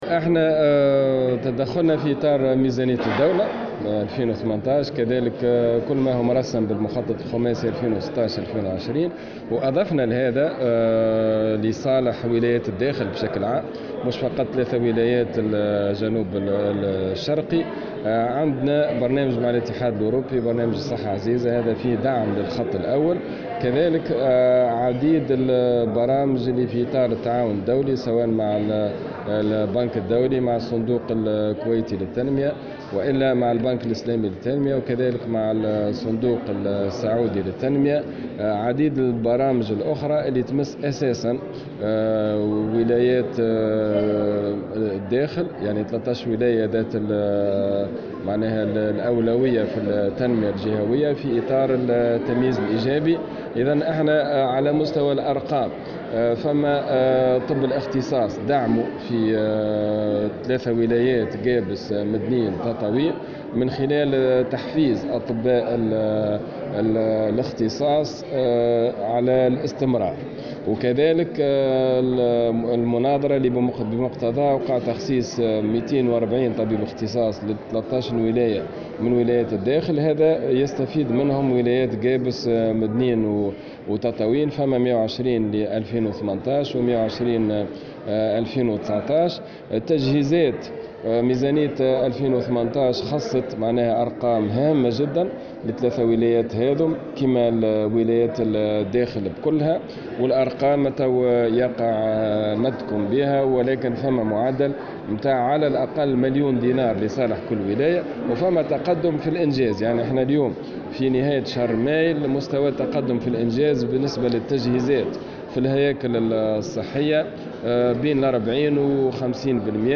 قال وزير الصحة عماد الحمامي، في تصريح لمراسلة الجوهرة اف ام خلال حضوره جلسة عامة بمجلس نواب الشعب خصّصت للنظر في تقرير لجنة التنمية الجهوية بخصوص زياراتها الميدانية إلى ولايات الجنوب الشرقي، قابس ومدنين وتطاوين، إن وزارته تعمل على تنفيذ مشاريع في البنية التحتية والتجهيزات والأدوية ودعم الموارد البشرية الطبية وشبه الطبية، لفائدة الولايات المذكورة.